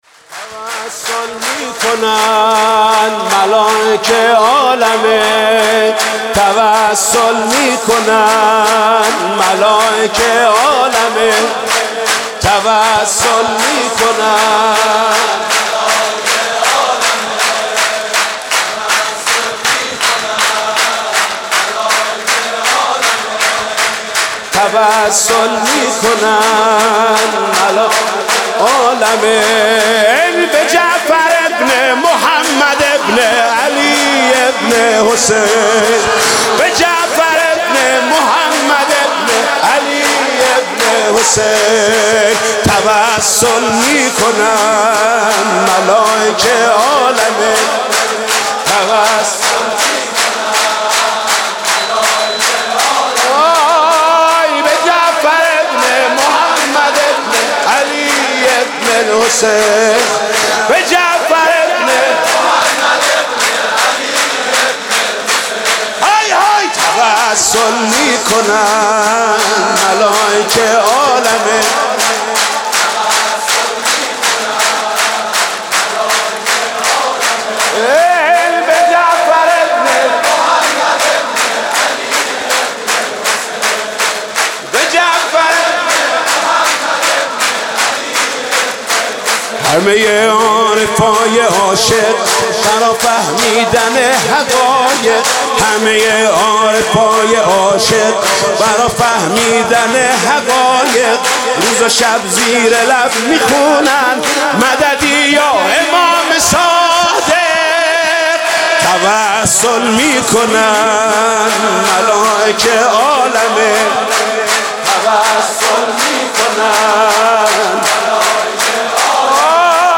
میلاد امام صادق (سرود)